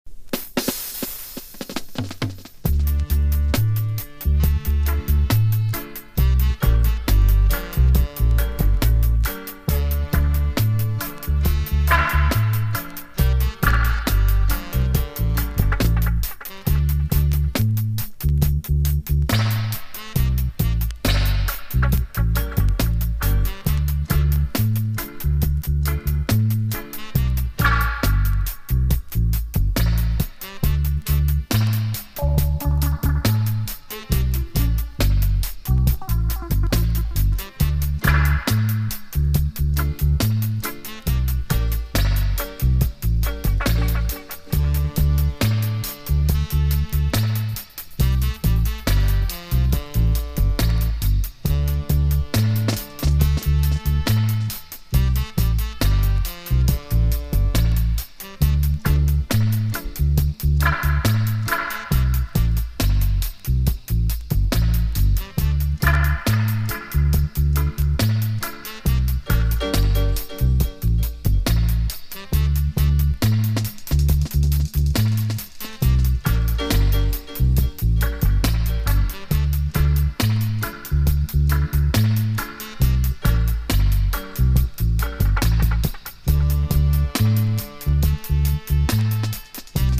初っ端からかなりぶっ飛んだダブ・ミックスですが、ポップに消化している洗練された内容は流石の一言!!
DUB / UK DUB / NEW ROOTS